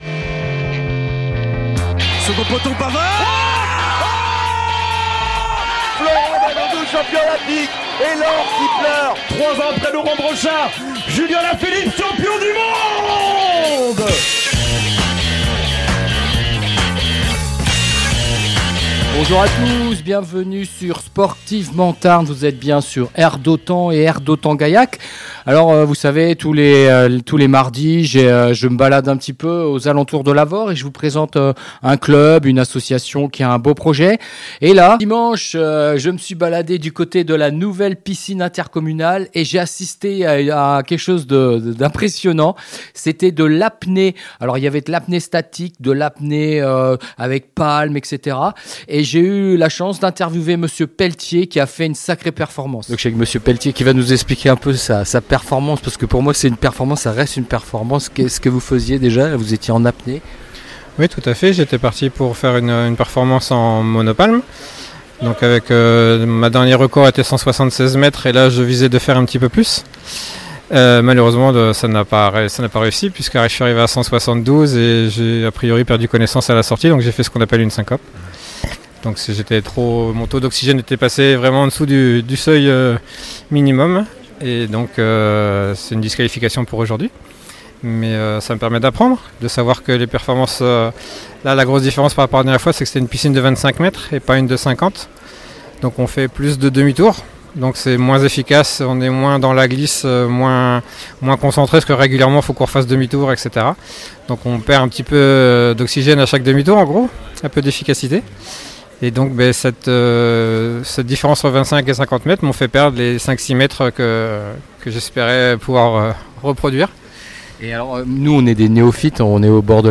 Le CSV à nouveau diffusé sur Radio d'Autan